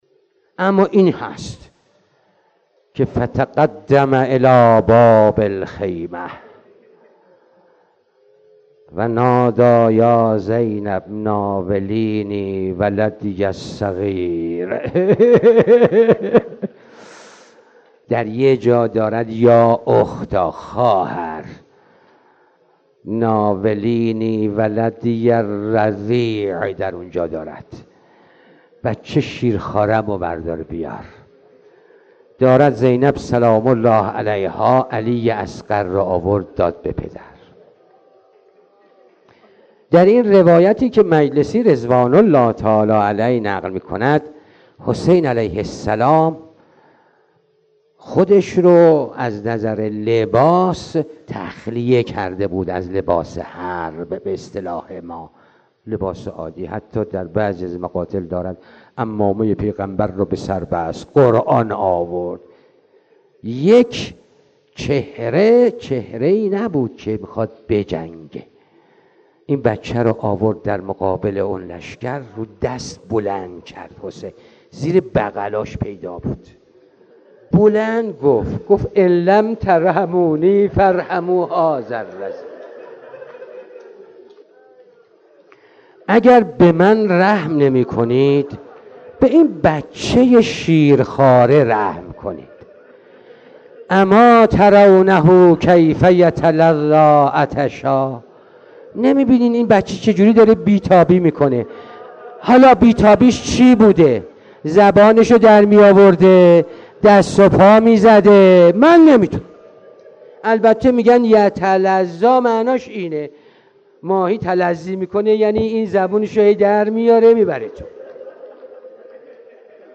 روضه حضرت علی‌ اصغر "ع" با صدای حاج آقا مجتبی تهرانی